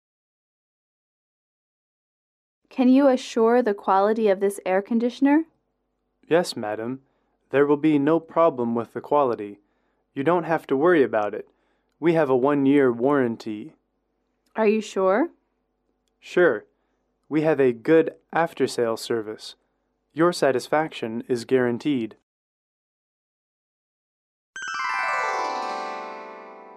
英语口语情景短对话03-3：因迟到而道歉